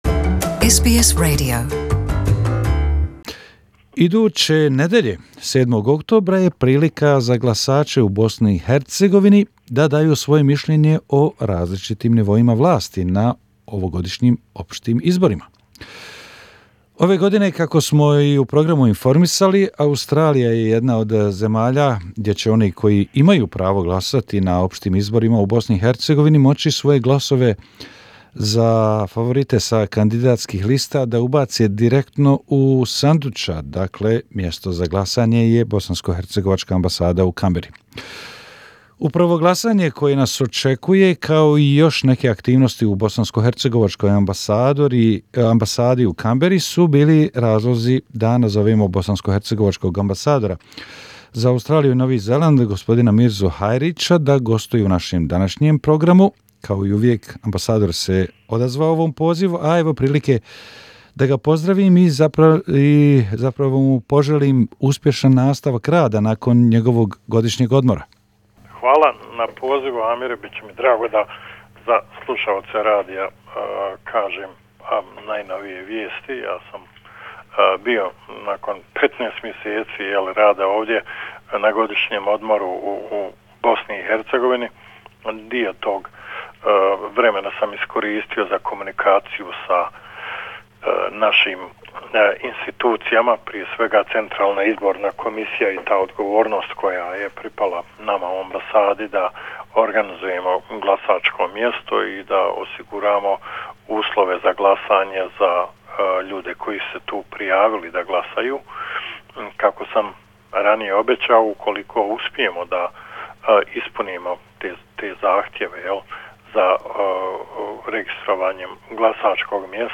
H.E. Mirza Hajric - interview